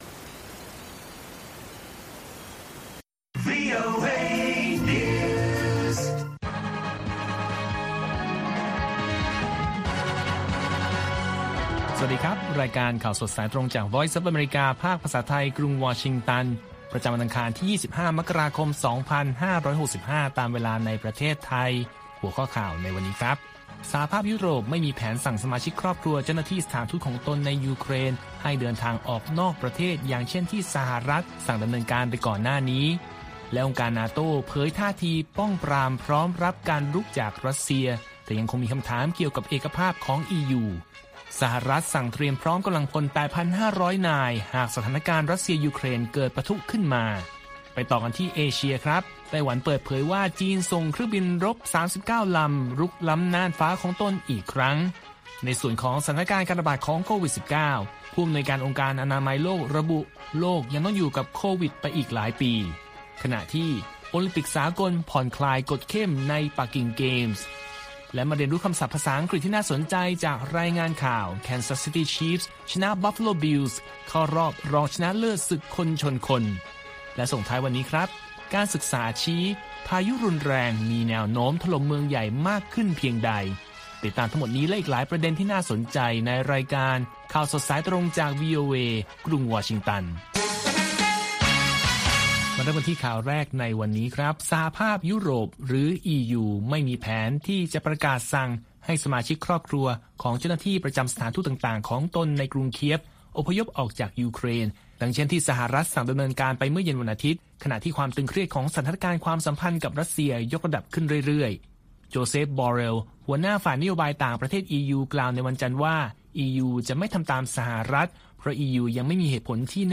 ข่าวสดสายตรงจากวีโอเอ ภาคภาษาไทย ประจำวันอังคารที่ 25 มกราคม 2565 ตามเวลาประเทศไทย